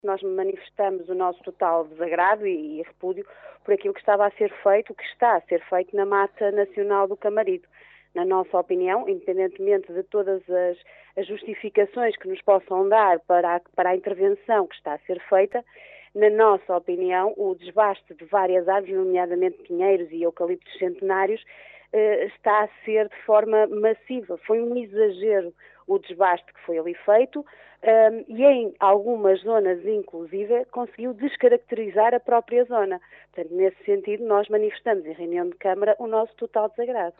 Os vereadores do PSD manifestaram ontem na Reunião de Câmara o seu total desagrado pelo desbaste de inúmeras árvores, nomeadamente “pinheiros e eucaliptos centenários”, que dizem estar a ser feito na Mata Nacional do Camarido, pelo Instituto de Conservação das Florestas e Natureza (ICNF).
Pela voz da vereadora Liliana Silva, o PSD manifestou o seu total repúdio face à intervenção que está a ser feita na Mata Nacional do Camarido.